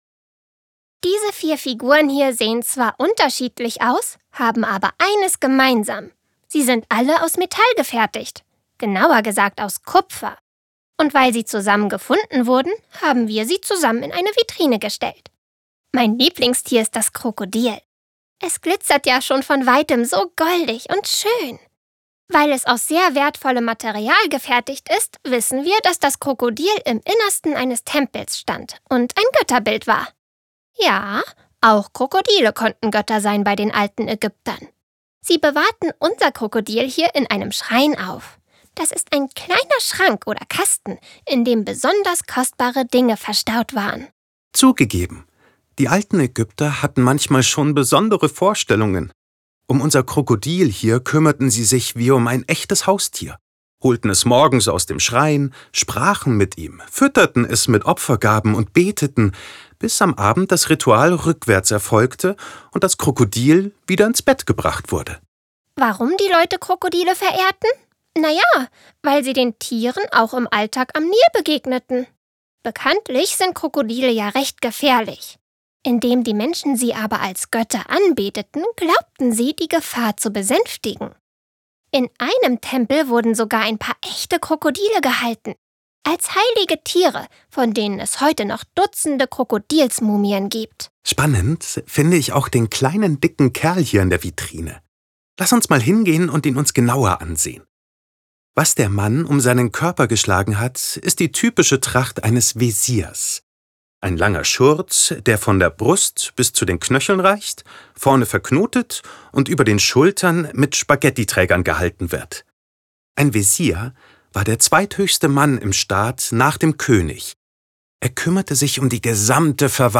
05_VR_AegyptischesMuseum_Multimediaguide_Kupferstatuen.wav